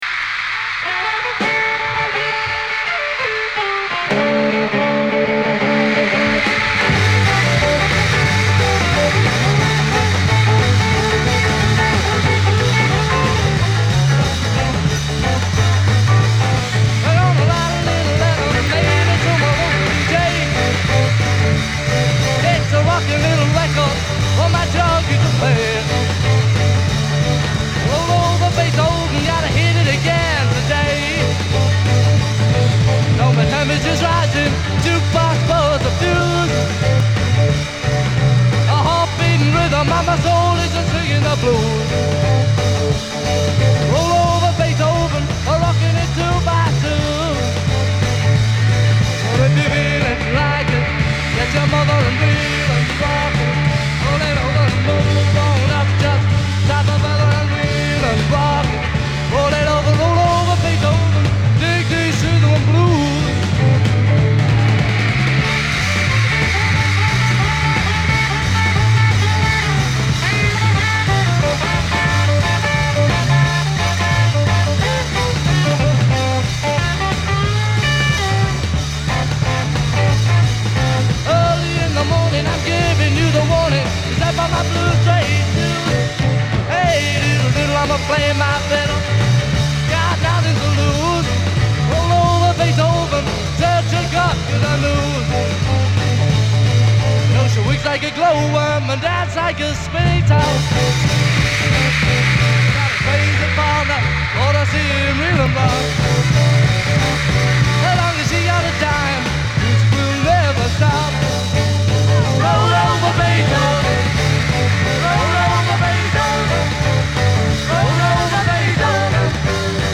virtually unlistenable
crowd noise